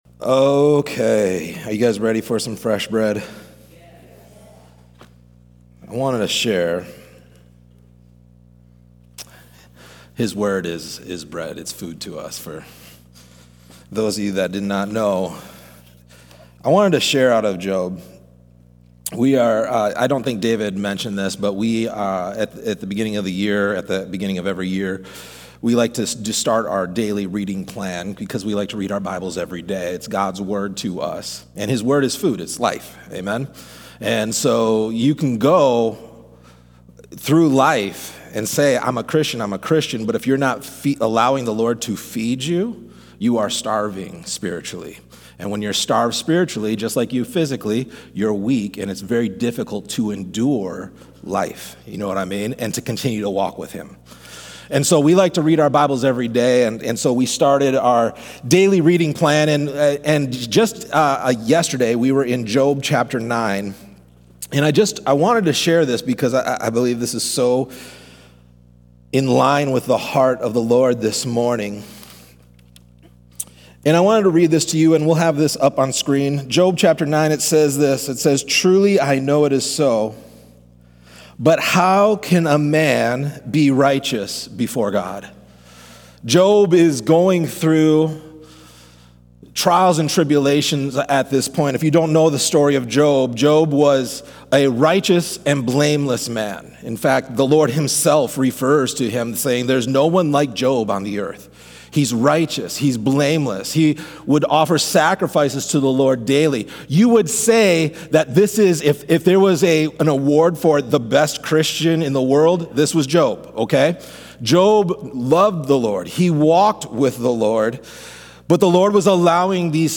A message from the series "Names Of God."